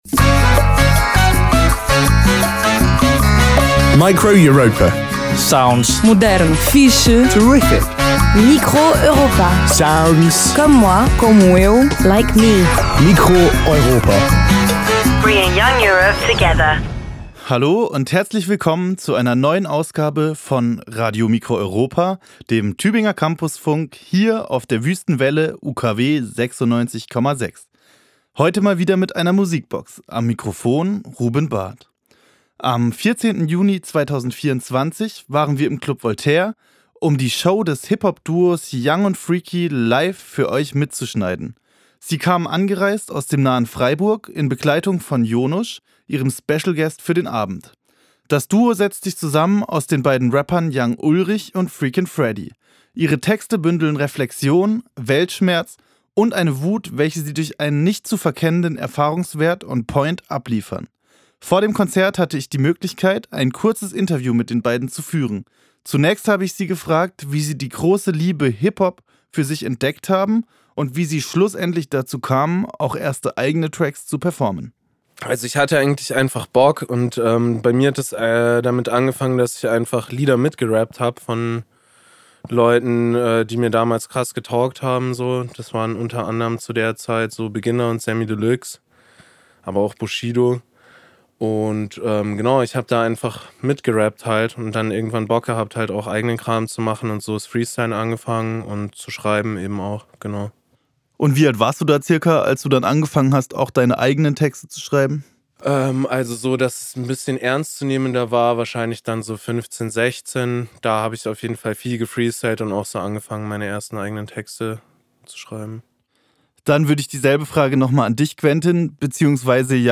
Hiphop Duos
Live-Aufzeichnung, geschnitten